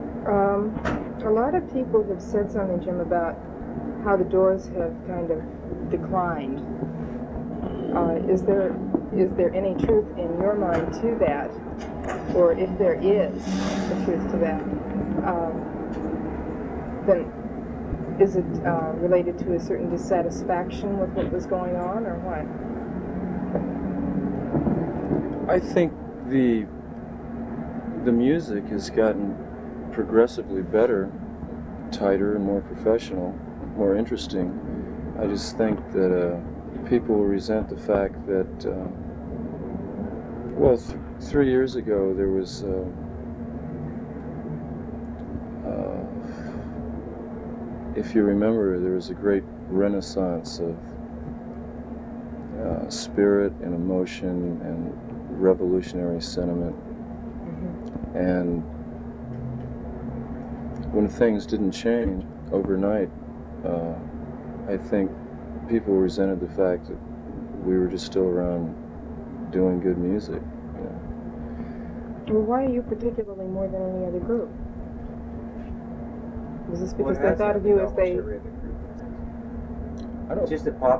13 People Say the Doors Have Declined (The Lost Interview Tapes - Volume Two).flac